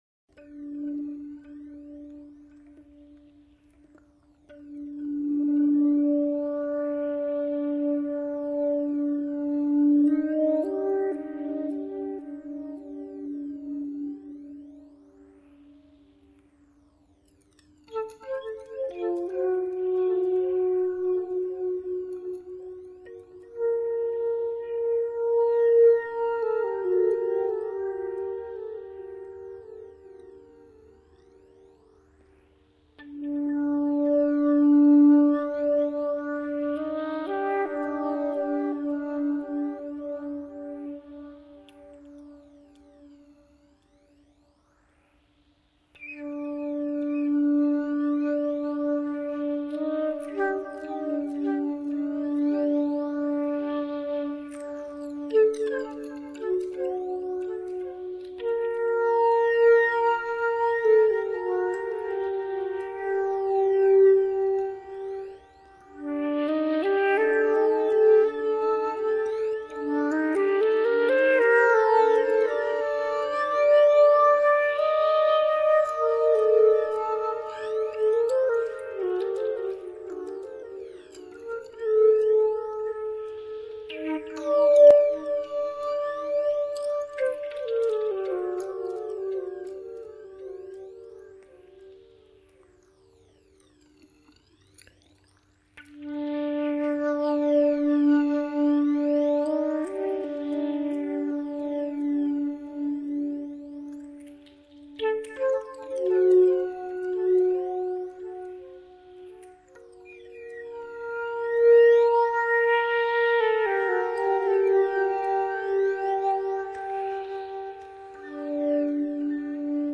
- intrumental - flauto solo & electronics